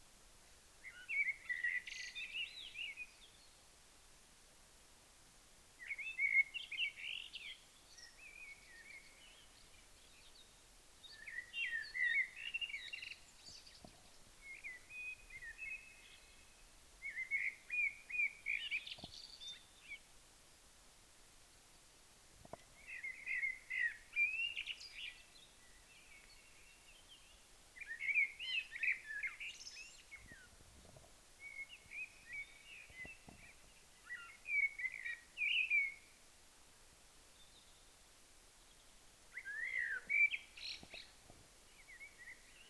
نام انگلیسی : Eurasian blackbird
نام علمی :Turdus merula